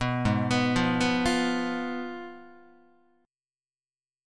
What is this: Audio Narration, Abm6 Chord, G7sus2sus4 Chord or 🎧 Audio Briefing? Abm6 Chord